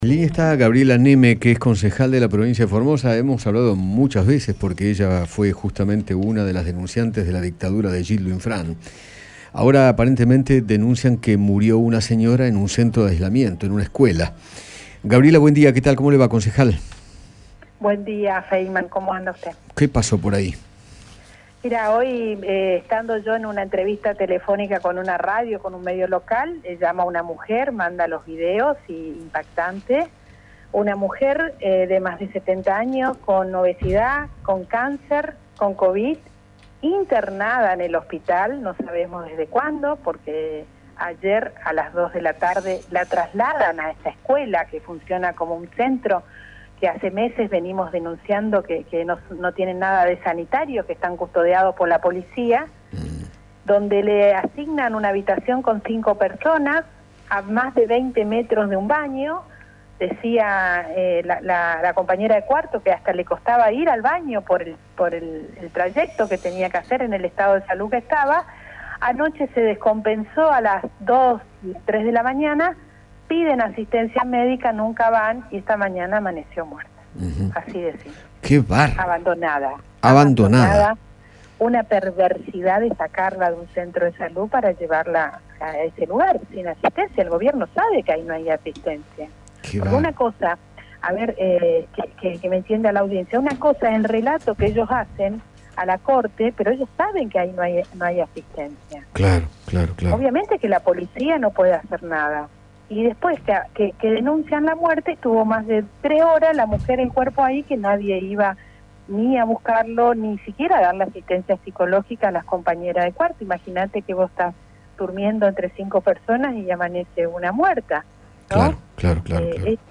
Gabriela Neme, concejal de Formosa, conversó con Eduardo Feinmann sobre el triste hecho que ocurrió anoche en uno de los centros de aislamiento de aquella provincia.